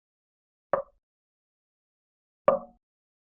Звуки бочки
Тихие постукивания по деревянной бочке